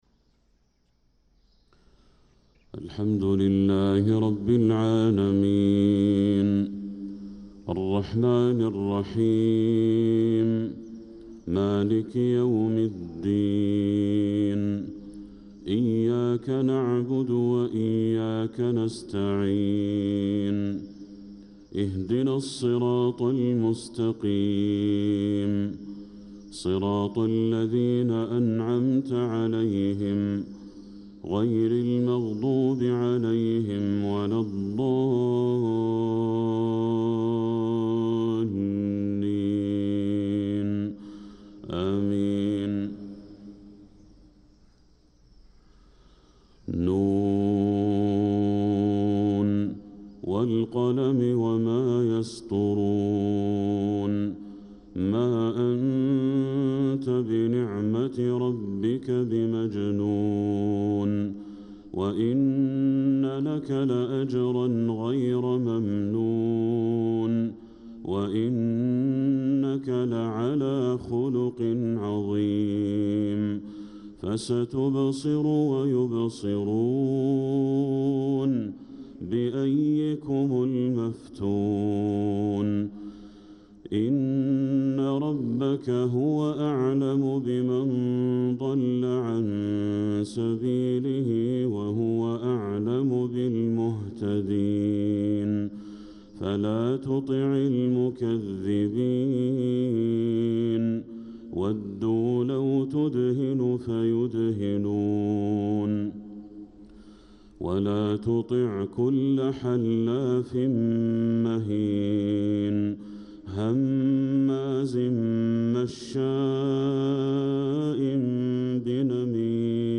صلاة الفجر للقارئ بدر التركي 25 ربيع الآخر 1446 هـ
تِلَاوَات الْحَرَمَيْن .